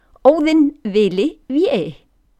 Listen to pronunciation: Óðinn Vili Véi